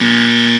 buzzer.wav